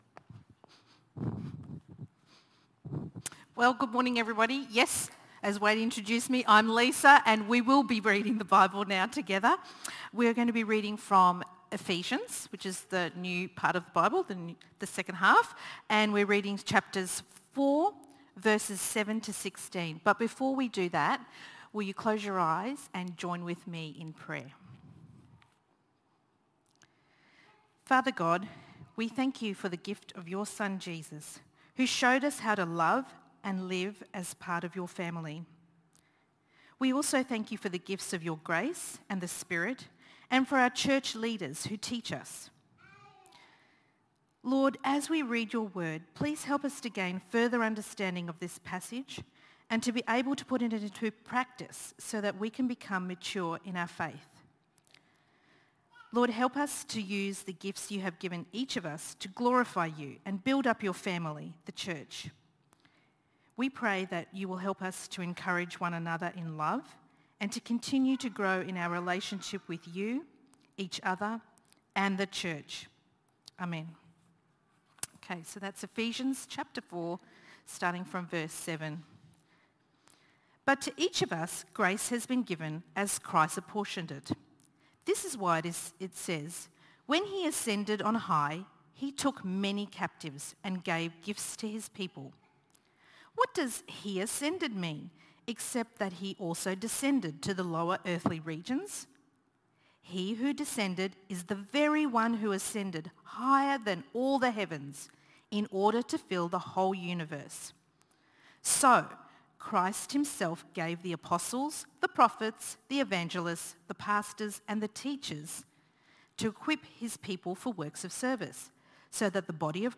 Growing to Maturity | Ephesians Week 9 | 13 September Livestream